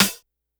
snr_65.wav